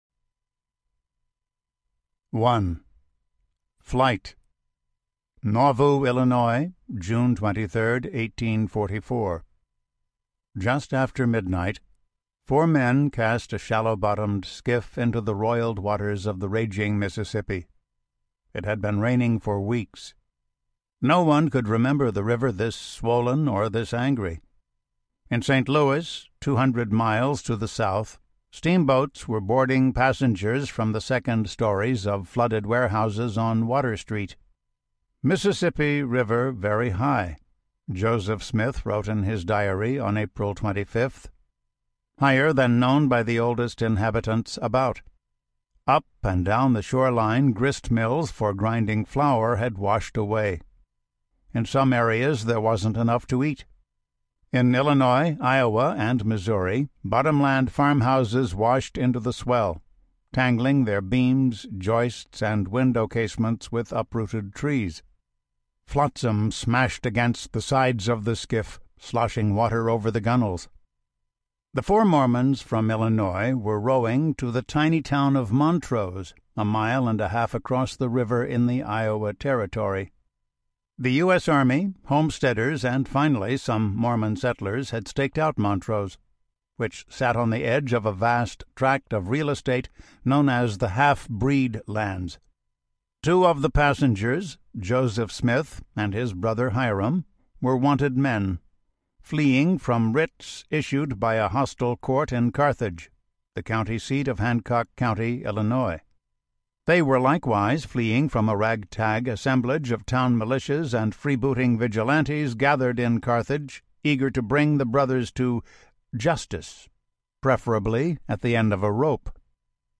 American Crucifixion Audiobook
Narrator